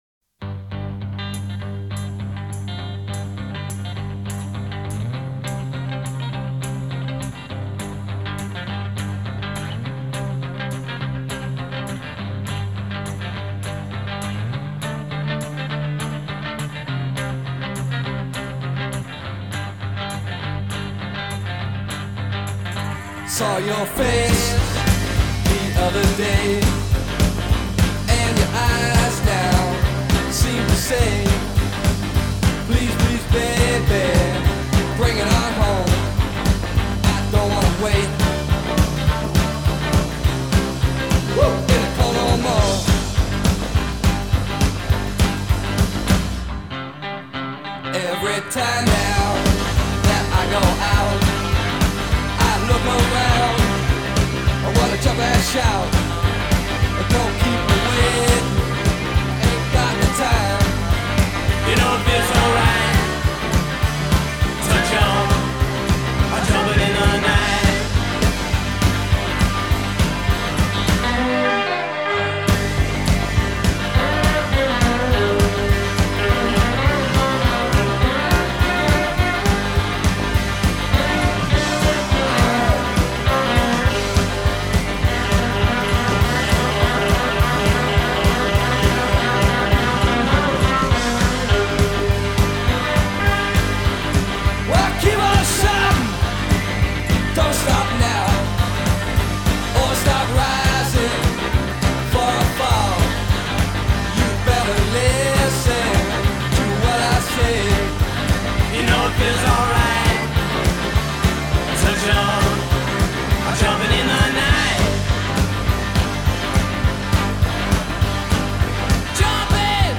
Power Pop Punk-Rock